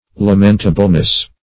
Lam"en*ta*ble*ness, n. -- Lam"en*ta*bly, adv.
lamentableness.mp3